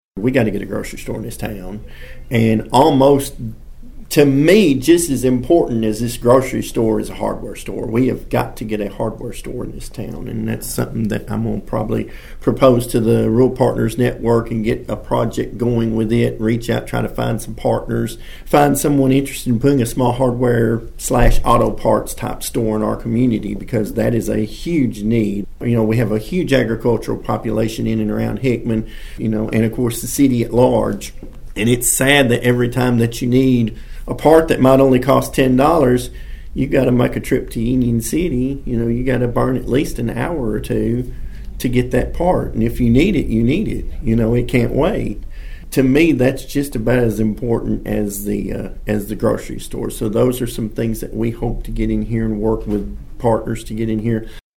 The new City Manager said Hickman has some immediate needs that he hopes to help with.(AUDIO)